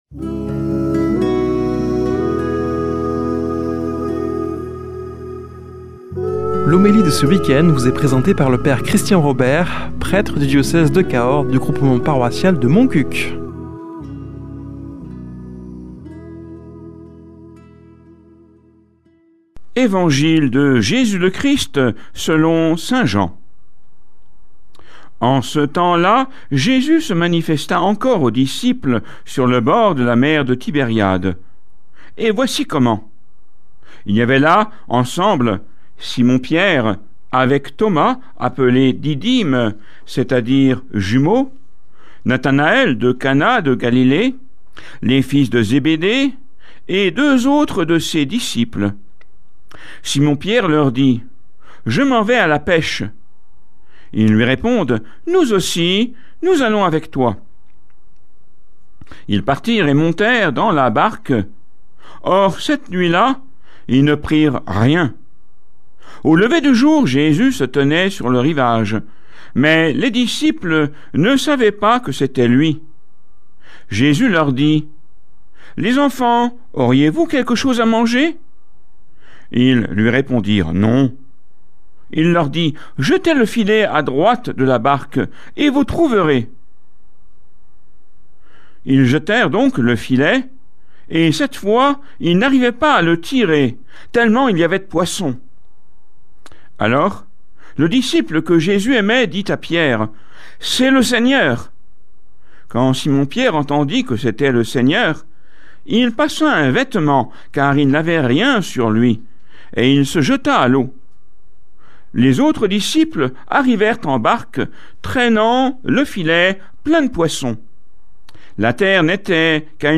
Homélie du 03 mai